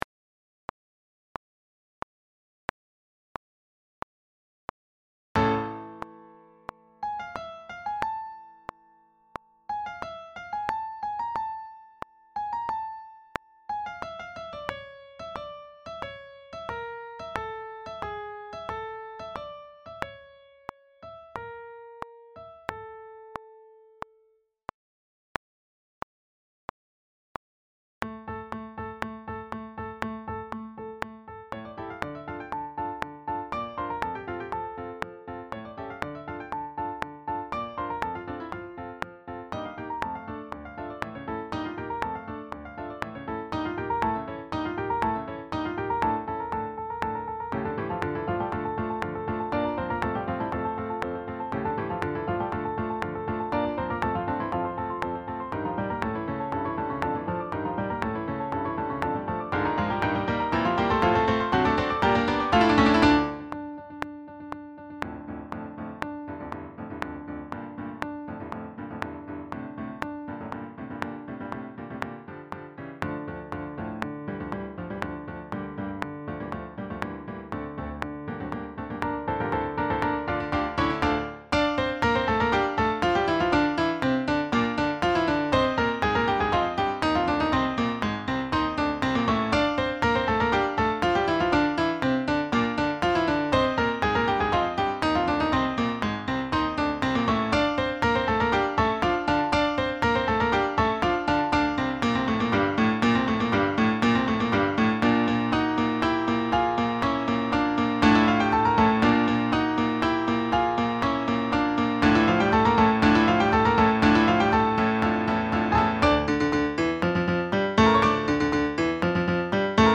Sax Choir
Depicting an increasingly exuberant ritual in celebration of the Greek God Bacchus, Saint-Saën's Bacchanale is fast and fun (and loud) and gives all of the parts a lot to play. In this arrangement the various melodies are spread across the parts from sopranissimo down to contrabass (if you are lucky enough to have them), with sectional moments and soloistic phrases. The eastern influences show through in some of the sinuous themes but the climactic ending is western romantic music at its finest.
Backing track
160-4-bacchanale-backing-track.mp3